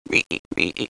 Frog 2: Instant Play Sound Effect Button